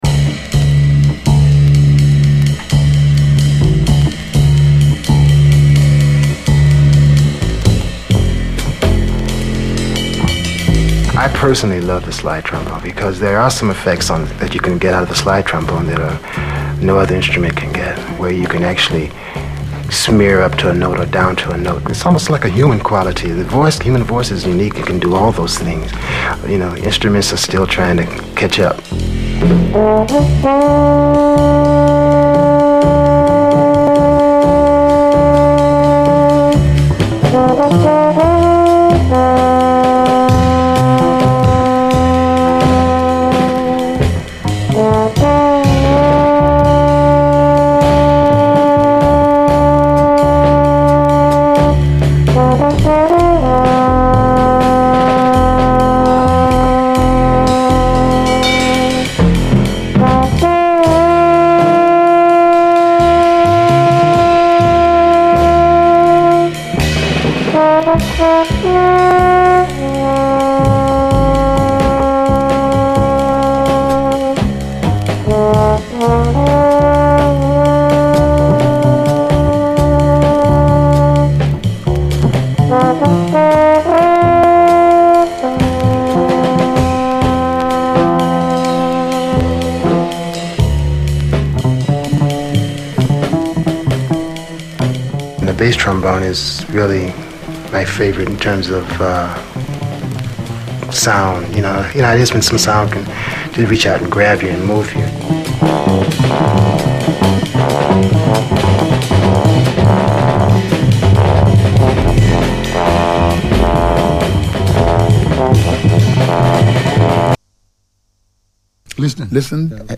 痺れ上がるほどにカッコいい内容のUKメロウ・シンセ・ソウル集です！
スカスカとチープなようでいて真っ黒く鬼メロウ、都会の闇に溶ける深いシンセの響きにヤラれます。